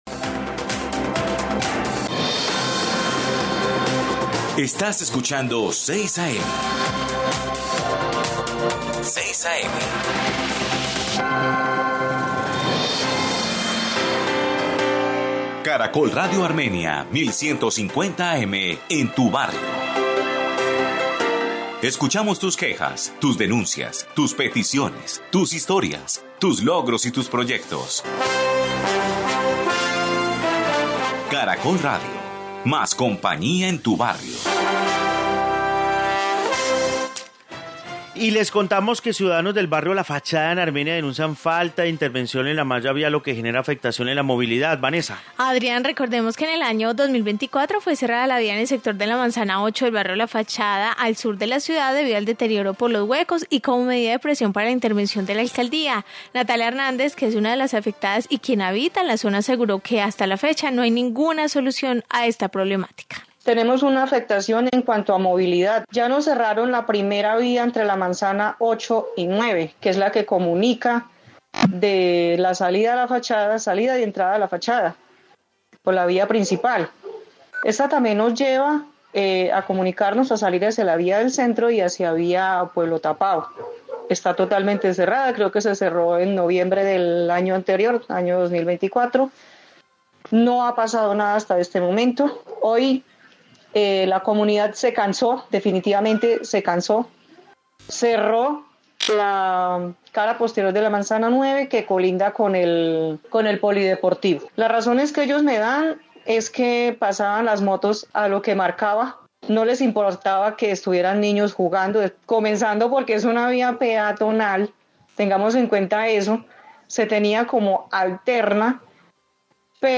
Informe barrio La Fachada de Armenia